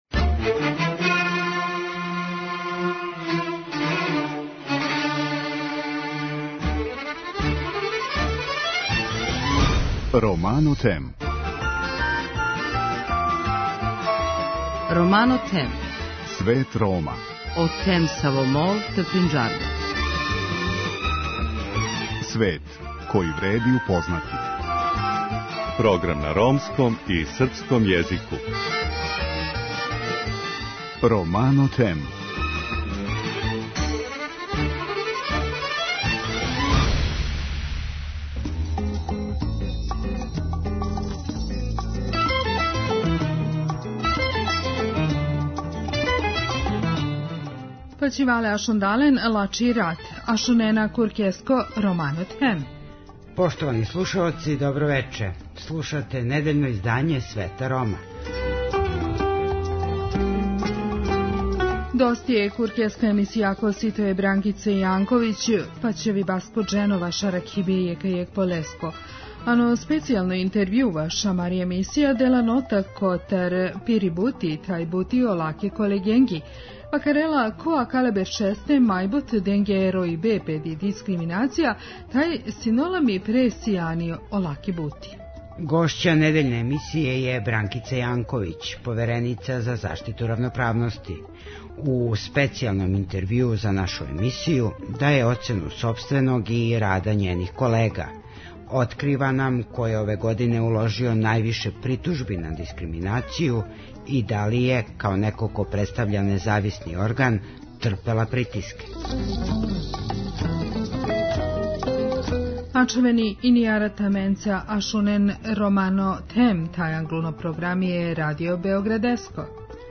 Гошћа недељње емисије је Бранкица Јанковић, повереница за заштиту равноправности. У специјалном интервјуу за нашу емисију даје оцену сопственог и рада њених колега. Открива нам ко је ове године уложио највише притужби на дискриминацију, и да ли је, као неко ко представља независни орган, трпела притиске.